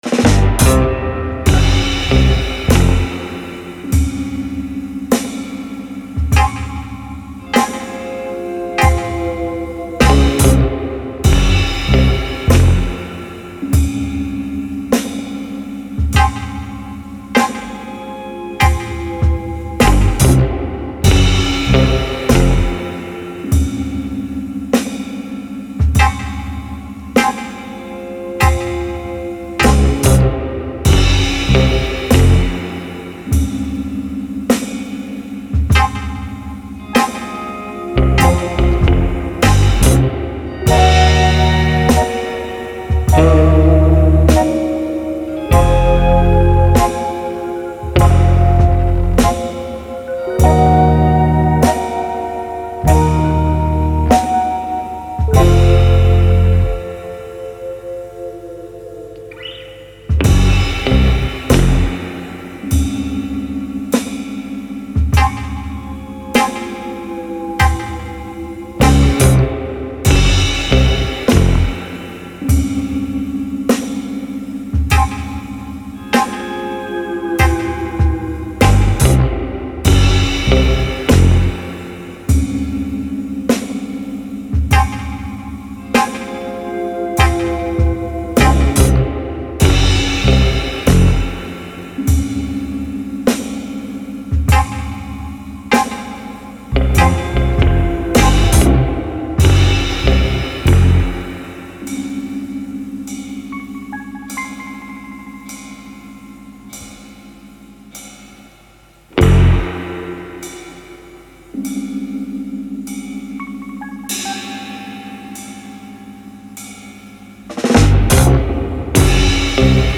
Spine-tingling spooky film noir drama.